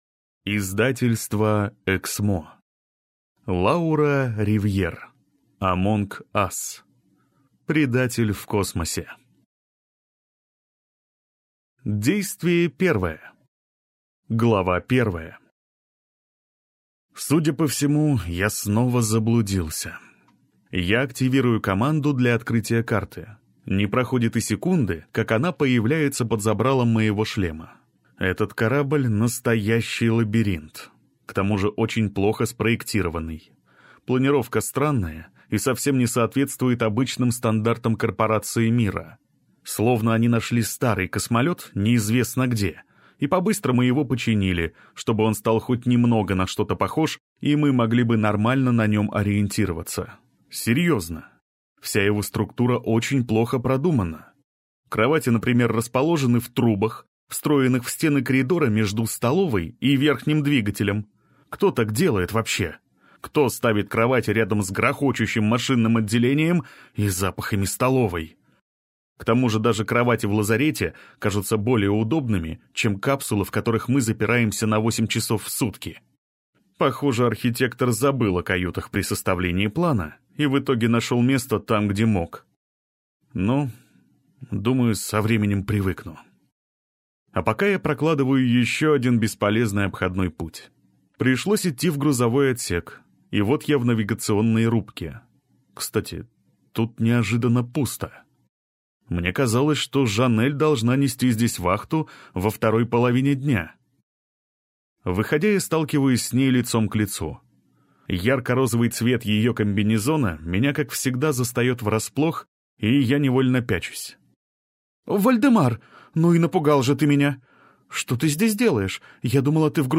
Аудиокнига АМОНГ АС. Предатель в космосе | Библиотека аудиокниг